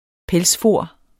Udtale [ ˈpεlsˌfoˀɐ̯ ]